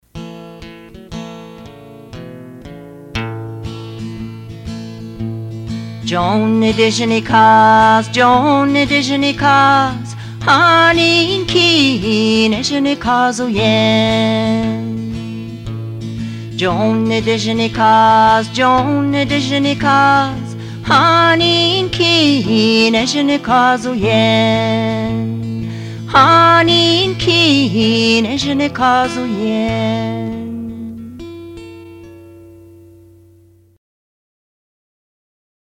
Nidijinikàz (comptine)
Notakotcikan (Musique)